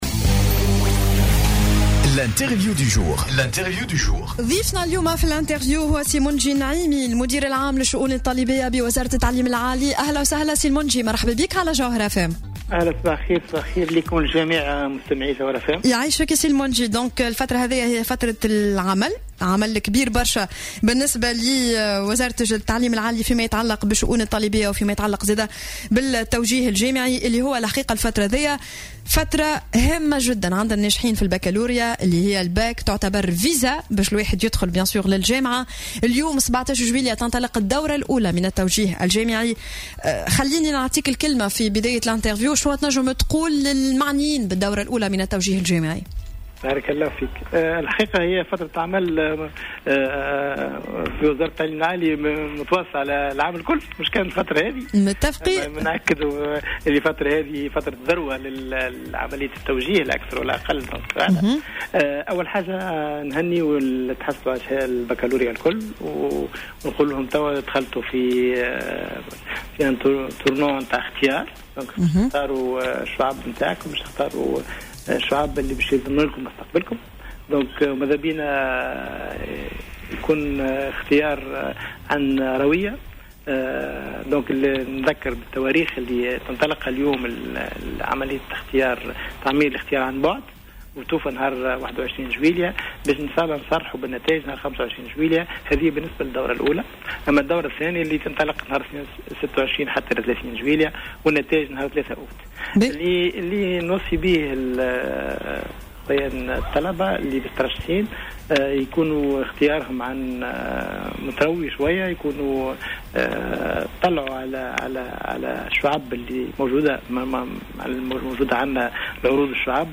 أكد المدير العام للشؤون الطلابية بوزارة التعليم العالي، منجي النعيمي، لـ "الجوهرة أف أم" انطلاق الدورة الأولى من التوجيه الجامعي اليوم الاثنين 17 جويلية 2017.